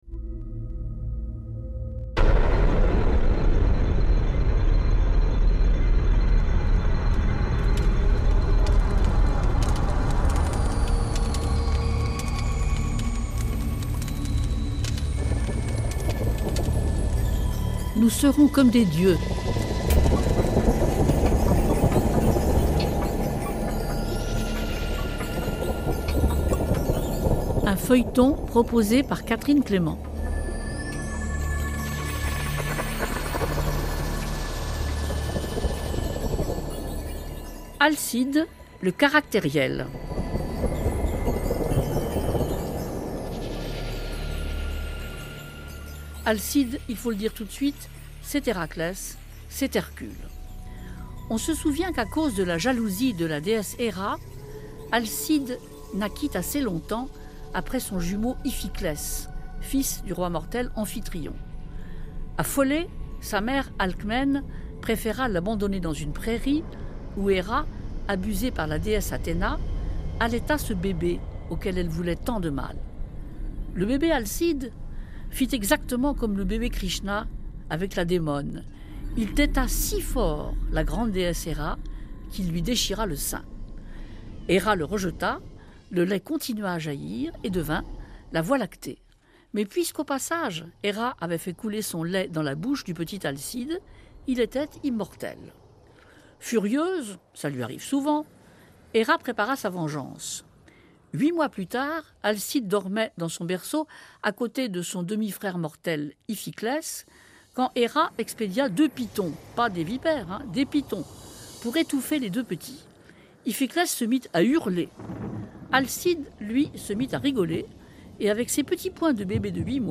Émission de radio